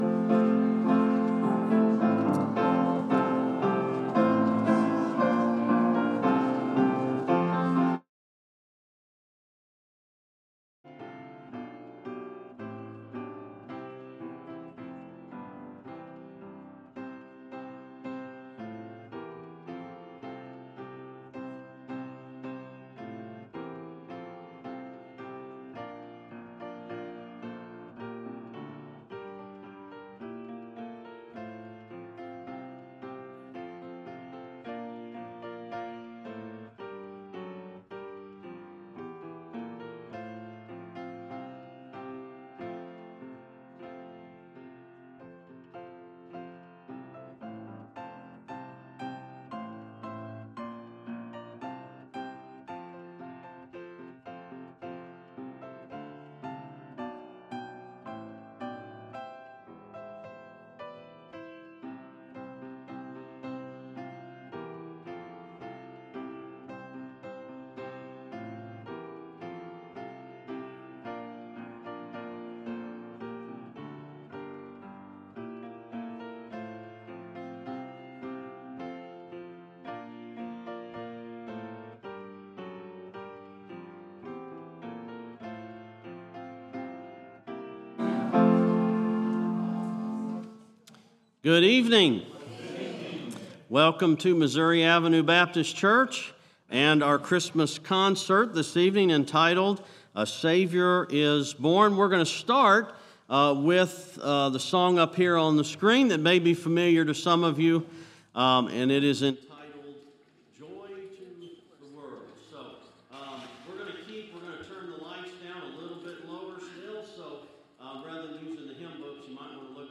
Christmas Concert 2025